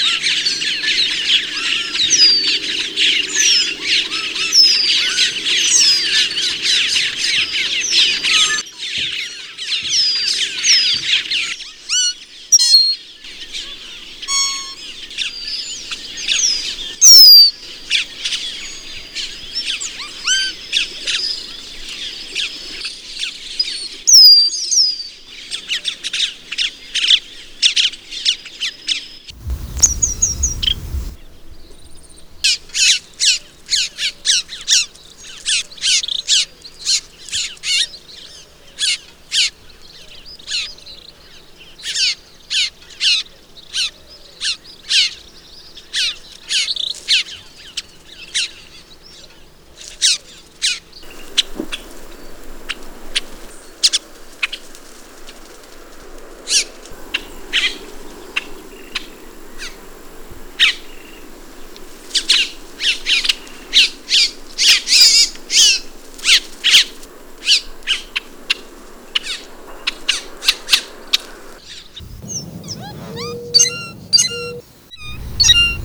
"Greater Antillean Grackle"
Quiscalus niger brachypterus